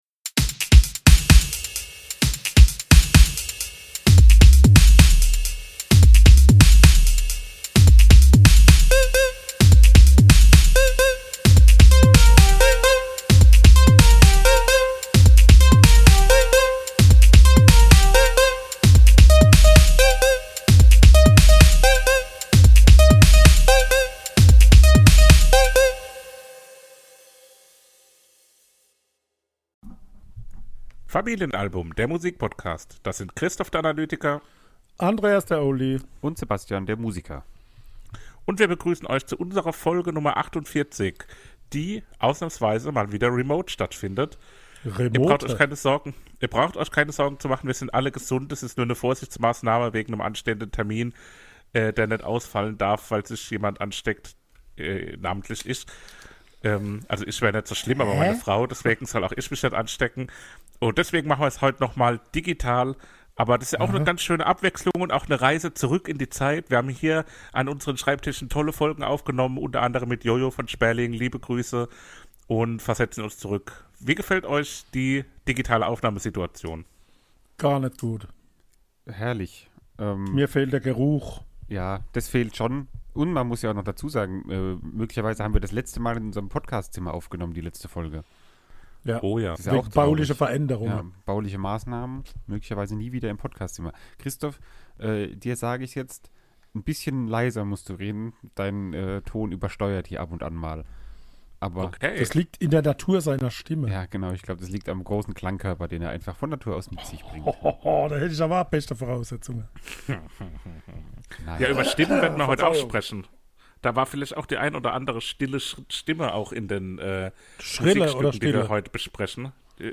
Das illustre Podcast-Trio versetzt sich zurück in die Zeit in der Aufnahmen nicht in einem Raum möglich waren und schaltet sich mithilfe moderner Technologie digital zusammen.